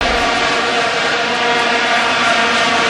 Something like the attached may be better; it's still fairly monotonic, but much more distinctive.
Regarding the specific "new test sound?" replacement proposed here, I agree that it has more "personality and punch" than the current version.  But it still sounds nothing at all like the other test clips.